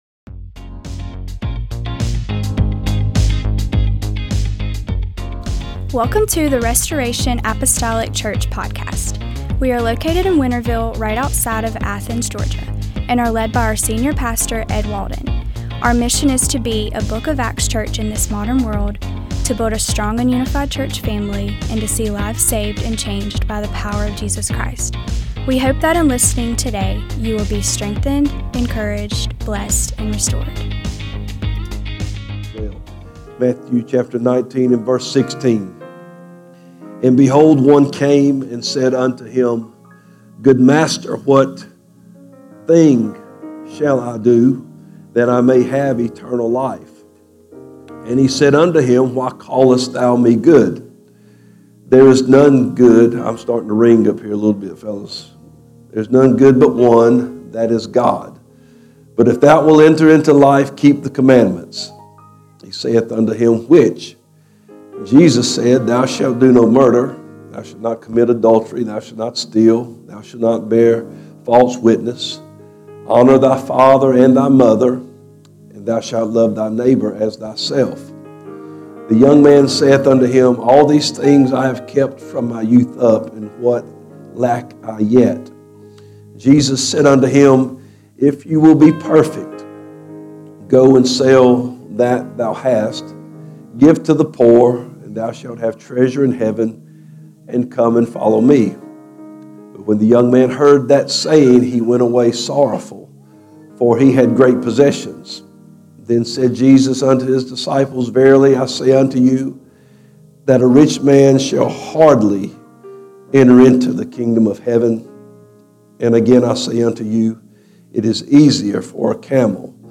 MDWK Service